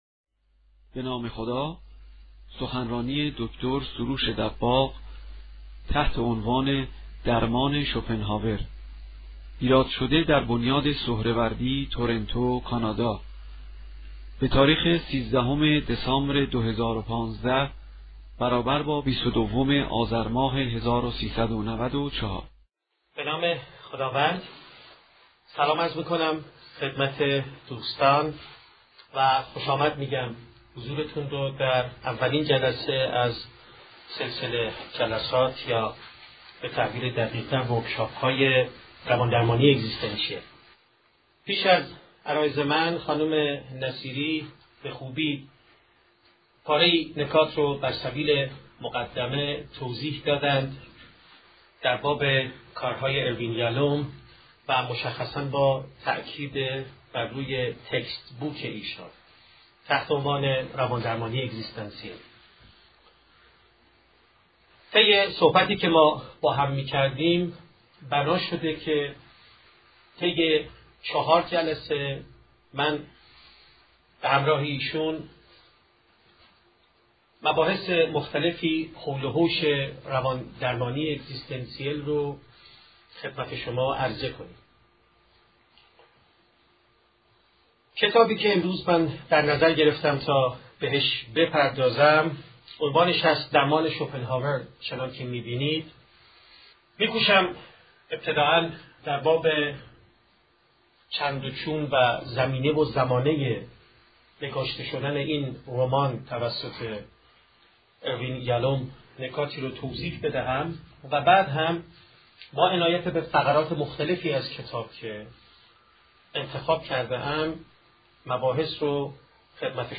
فایل صوتی نقد و بررسی کتاب درمان شوپنهاور اثر اروین دی یالوم توسط دکتر سروش دباغ در بنیاد سهروردی